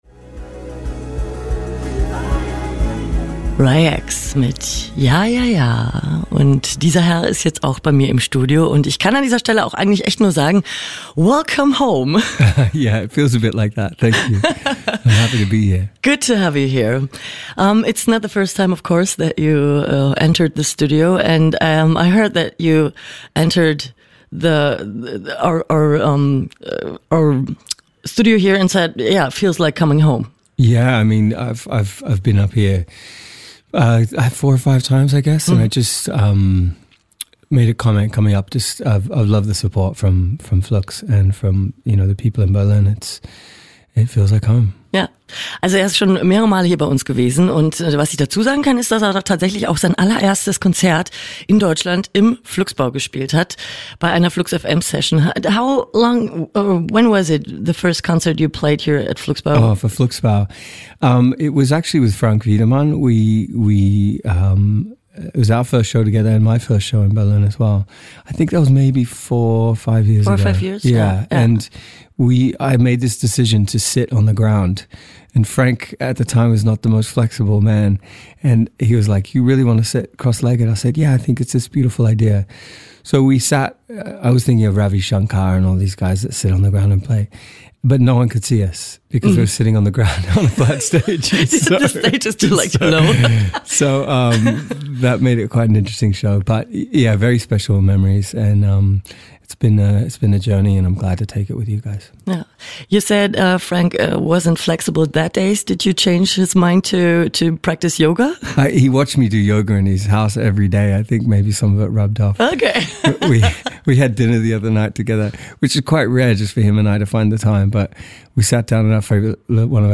Heute könnt ihr ihn im Interview hören und Karten fürs Konzert gewinnen.